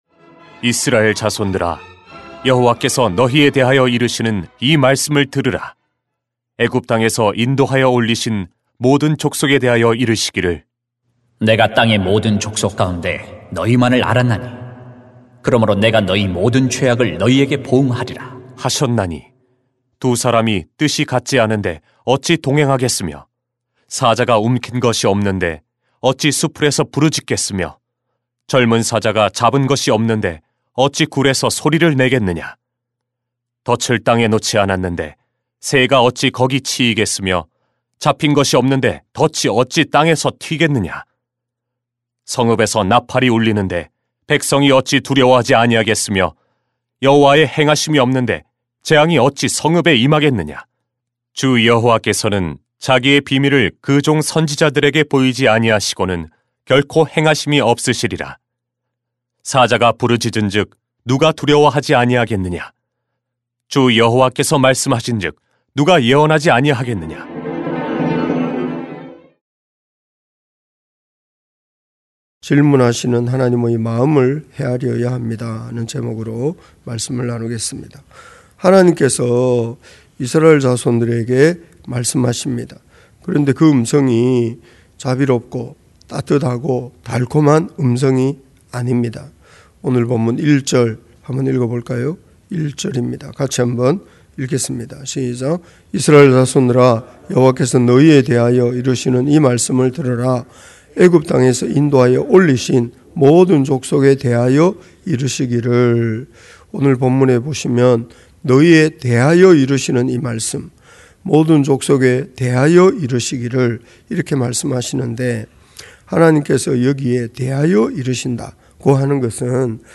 [암 3:1-8] 질문하시는 하나님의 마음을 헤아려야 합니다 > 새벽기도회 | 전주제자교회